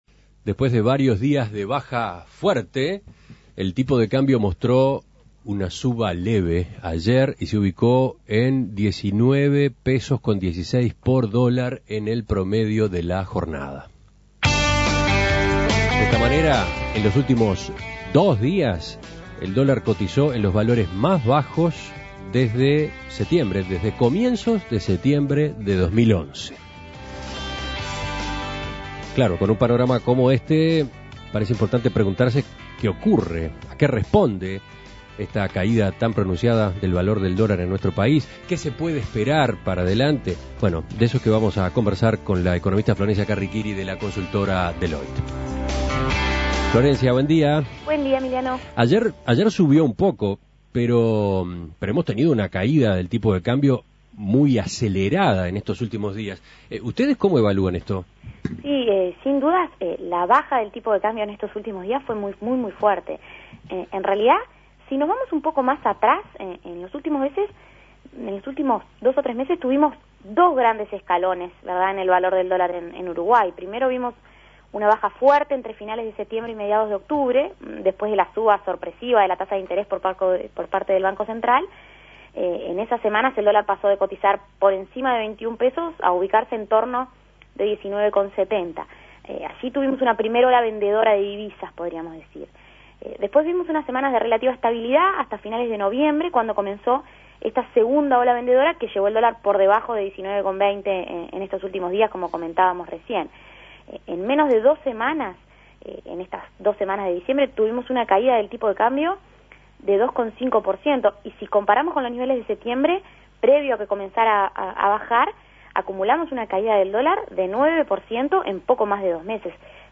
Análisis Económico ¿Cómo se explica la reciente baja del dólar en Uruguay?